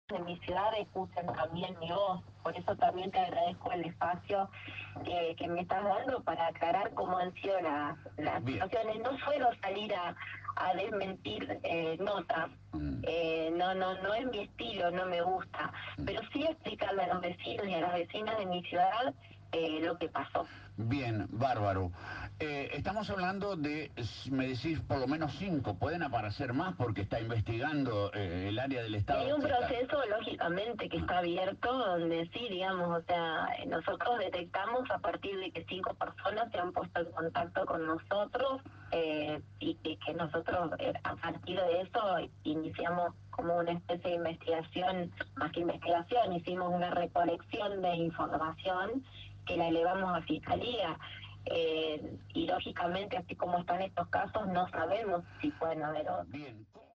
La vicegobernadora Laura Stratta eligió Radio LT 39 de Victoria para intentar minimizar el escándalo de los subsidios truchos que la tiene como protagonista y que ha generado un enorme escándalo en la ciudad de Victoria y en toda la Provincia.
Entrevista-a-Stratta-1.mp3